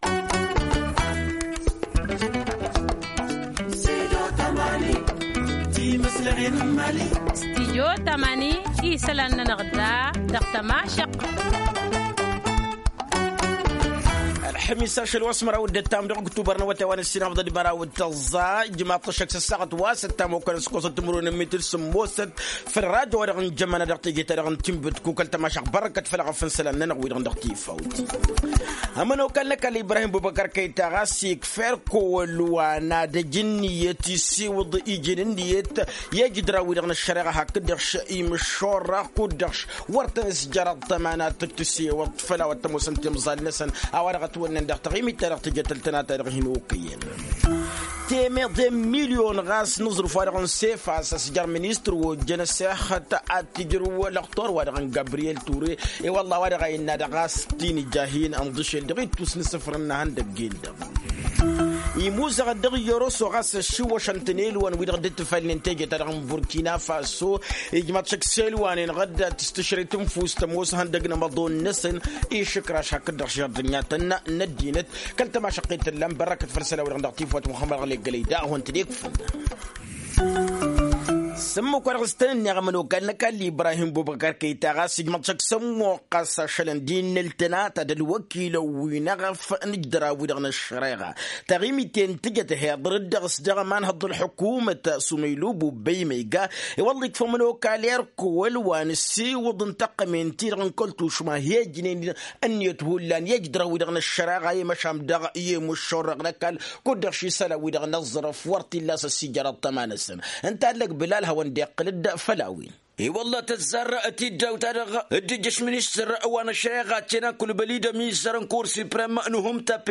Journal en français: Télécharger